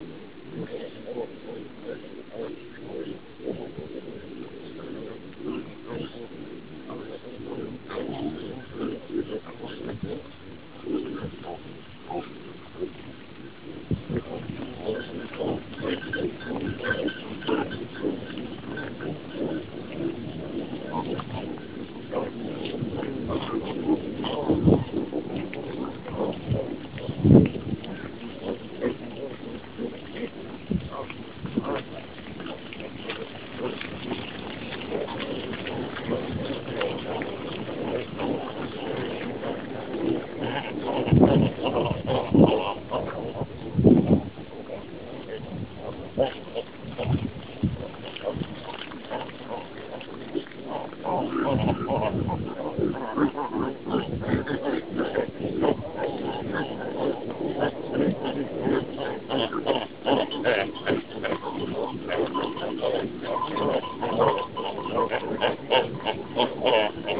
Lydopptak fra reingjerde.
Lydopptaket er tatt når det var kalvemerking ved Gæsasjávri sommeren 2006.